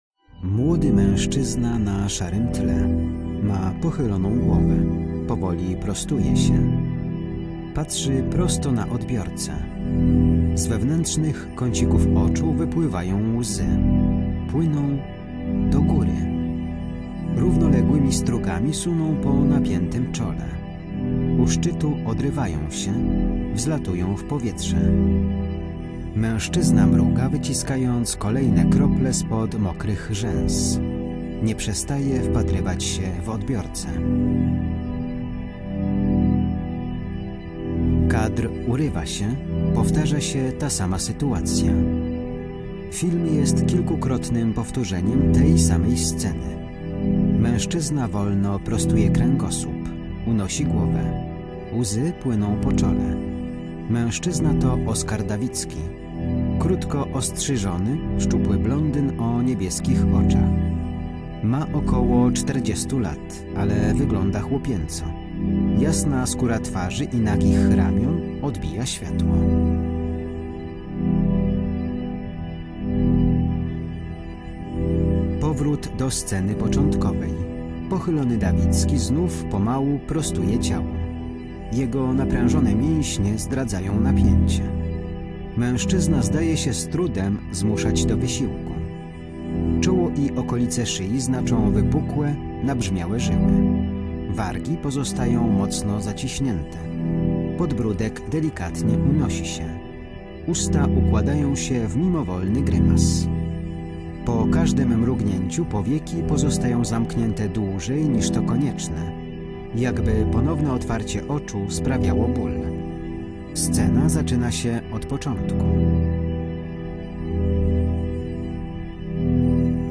Film z audiodeskrypcją
audiodeskrypcja